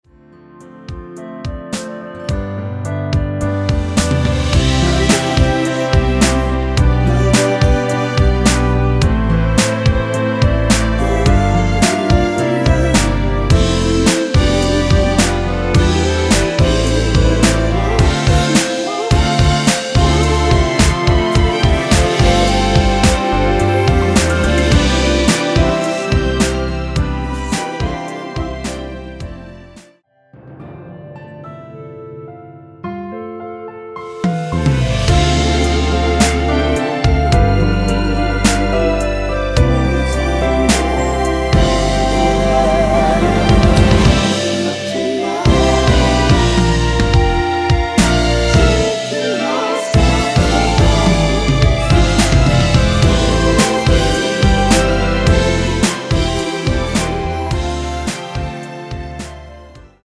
원키 멜로디와 코러스 포함된 MR입니다.(미리듣기 확인)
앞부분30초, 뒷부분30초씩 편집해서 올려 드리고 있습니다.